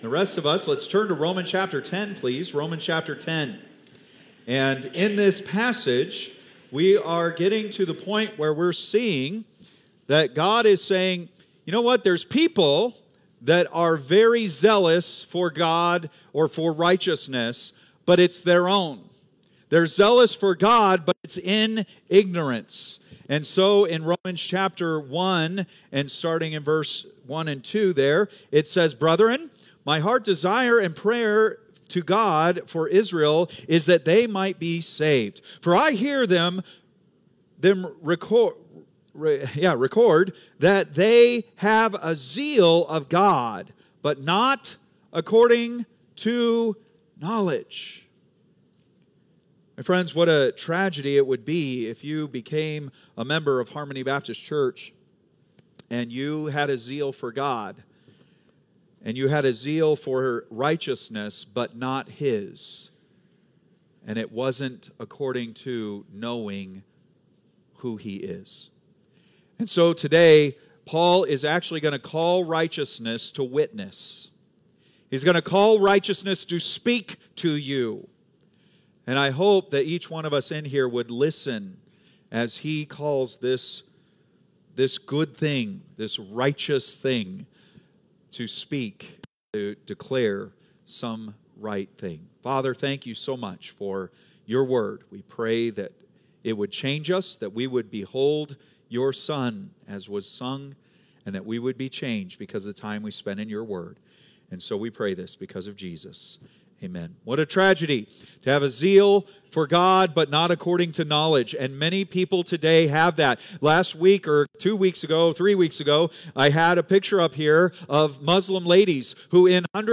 Date: February 23, 2025 (Sunday Morning)